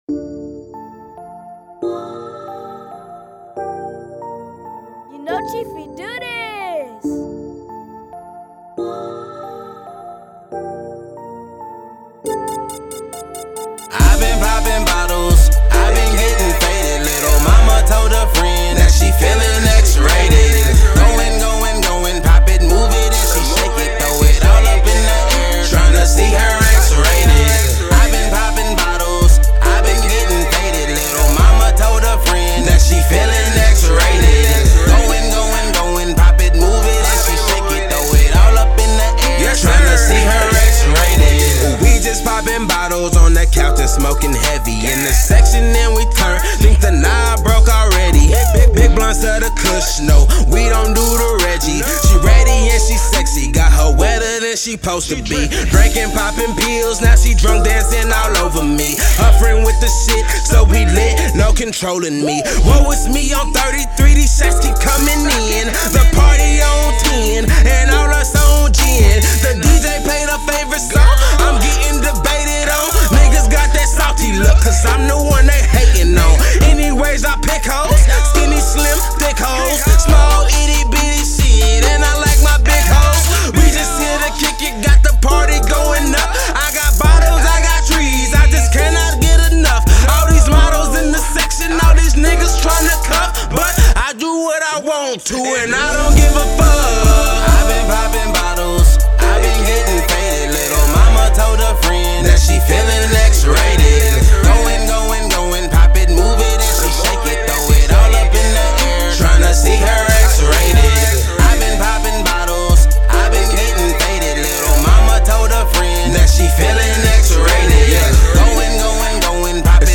Hiphop
rapper